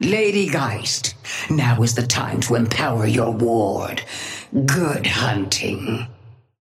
Sapphire Flame voice line - Lady Geist, now is the time to empower your ward. Good hunting.
Patron_female_ally_ghost_oathkeeper_5a_start_02.mp3